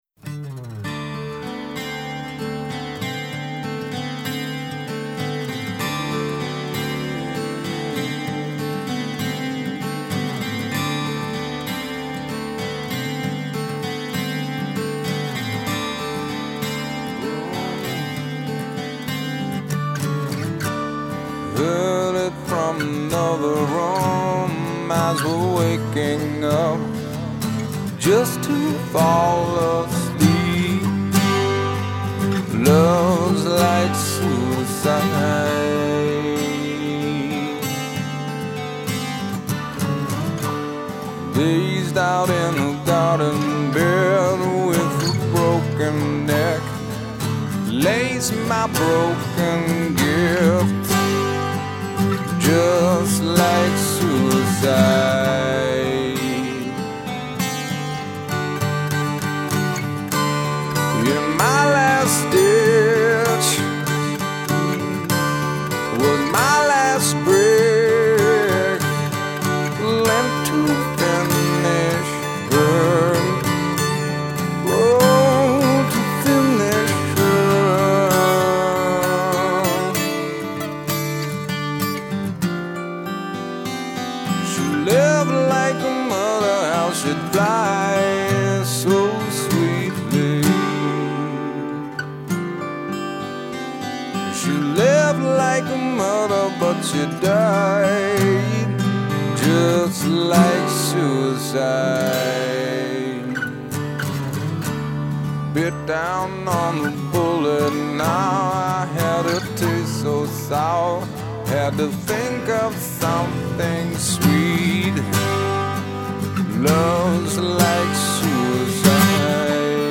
framed only by a twelve string guitar